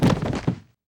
RagdollCollision.wav